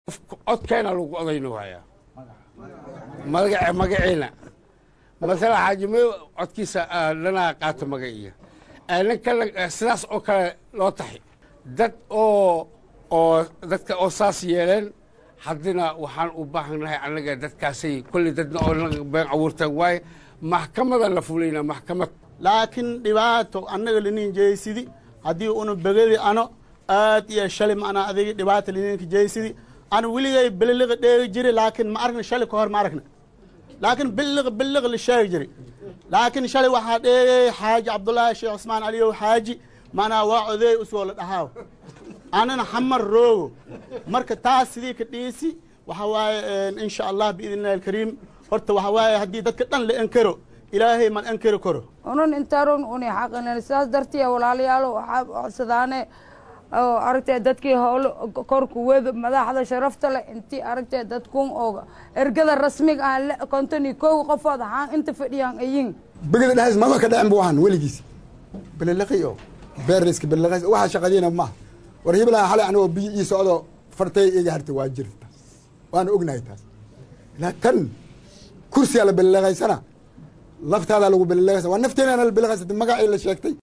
awdhegleKulan ay maanta Magaalada Muqdisho ku yeesheen Nabadoono, Waxgarad iyo Odayaal ka soo jeeda Beesha begedi oo ka mid ah beelaha dega deegaanada Koofur galbeed ayaa waxa ay Kulankooda ka sheegeen in la masuq maasuqay Kursigii ay ku lahaayeen Aqalka Hoose ee Baarlamaanka.